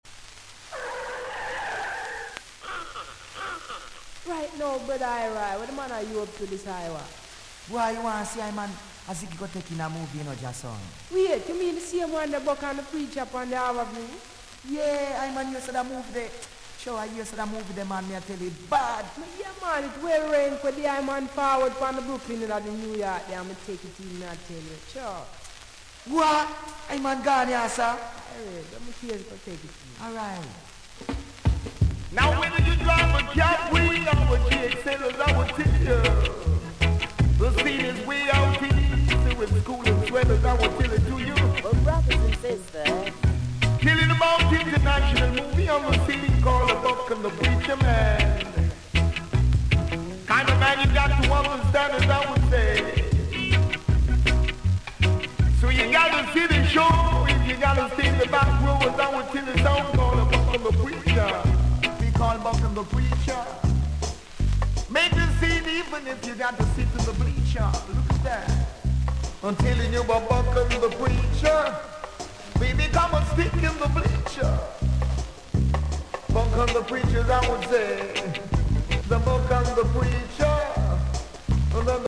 Notes: hissy press